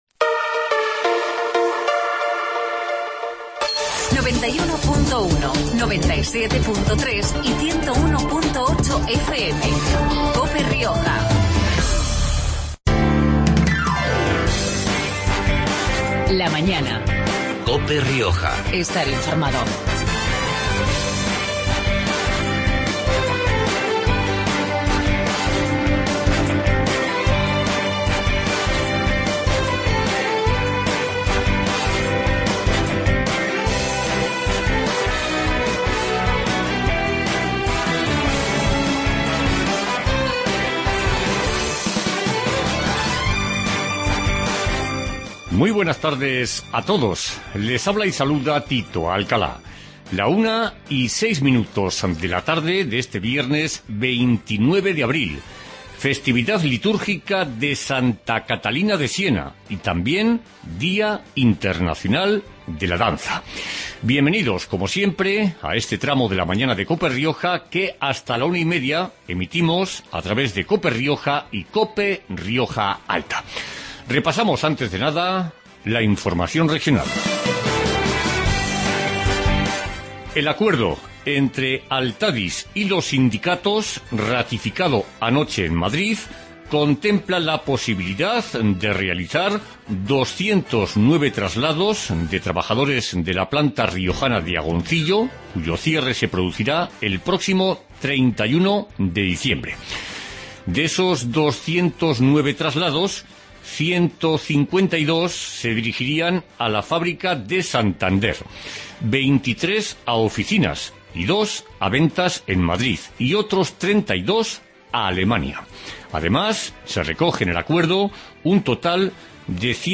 AUDIO: Magazine de actualidad riojana